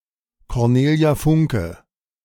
Cornelia Maria Funke[1] (German: [kɔʁˈneːli̯a ˈfʊŋkə]